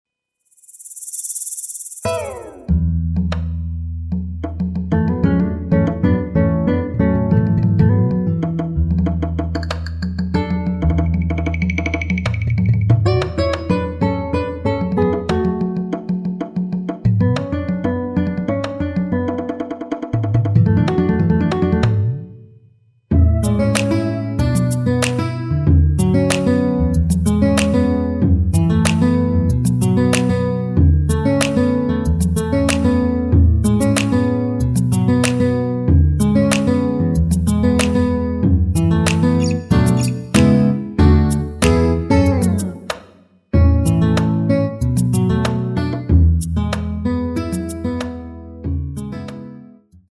노래 부르기 쉽게 첫 가사에 멜로디 넣었습니다.(미리듣기 참조)
앞부분30초, 뒷부분30초씩 편집해서 올려 드리고 있습니다.
중간에 음이 끈어지고 다시 나오는 이유는